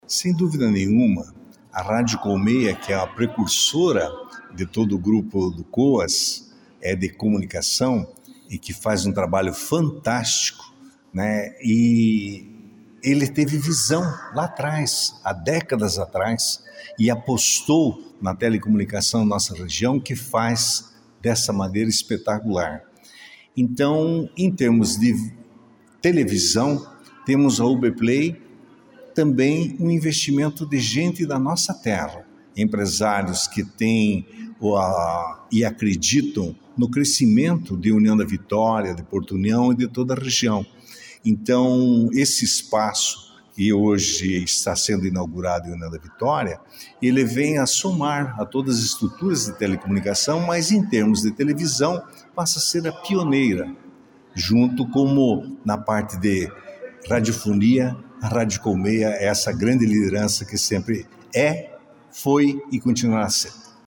Na noite desta quinta-feira (14), a TV UBPLAY inaugurou oficialmente seu novo e moderno prédio em União da Vitória.
Encerrando a noite, o prefeito de União da Vitória, Dr. Ary Carneiro Jr., ressaltou o pioneirismo da emissora na região, fazendo também referência à história da Rádio Colmeia como marco de comunicação local.//